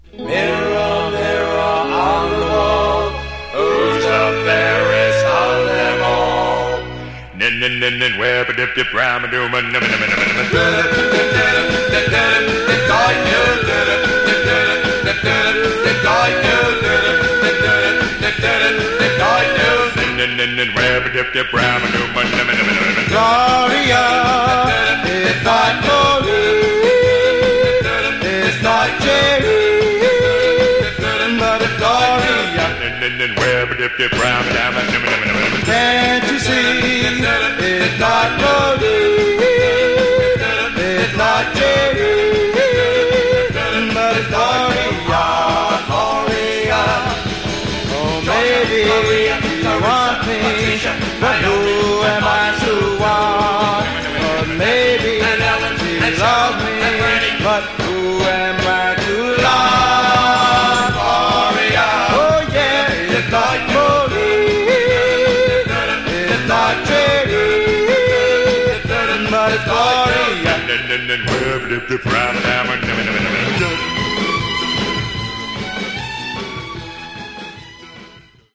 Genre: Vocal Groups (Doo-Wop)
This raucous uptempo rendition of the classic Doo Wop ballad